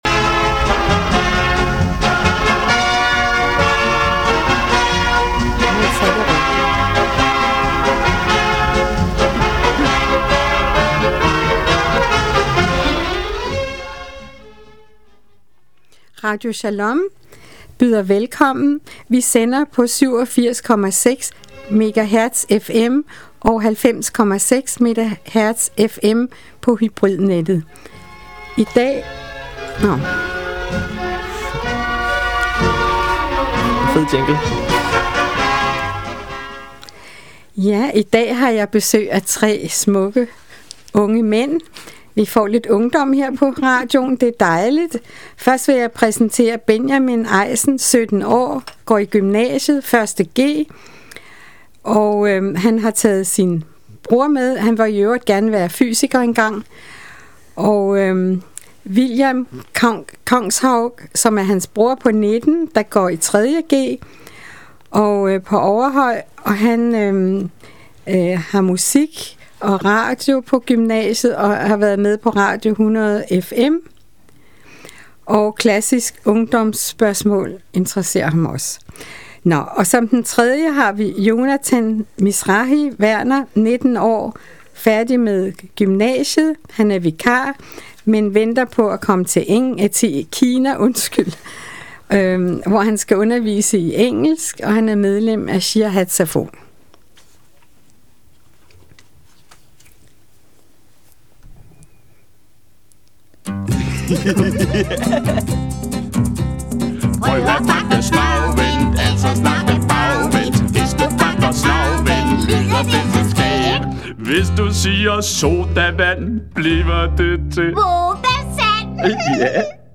Beskrivelse: Interview